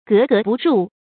gé gé bù rù
格格不入发音
成语正音 不；可以读作“bú”。